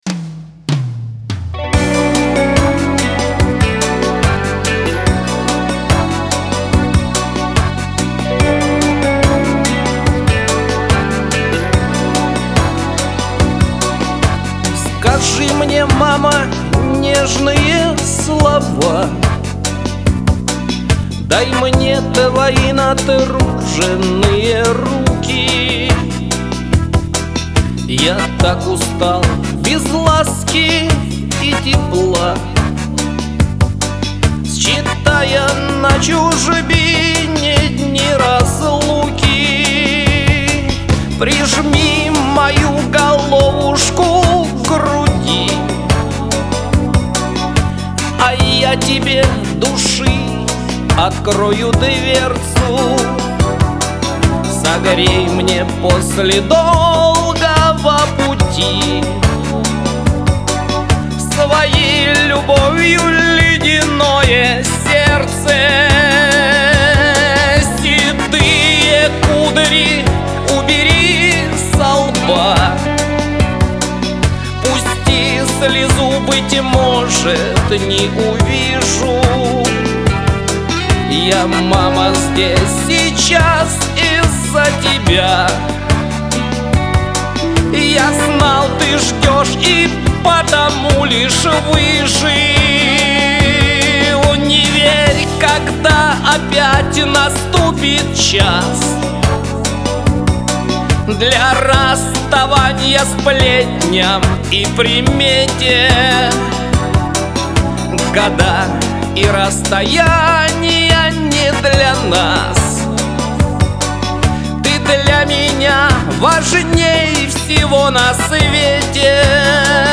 Шансон
Какой приятный, эластичный голос!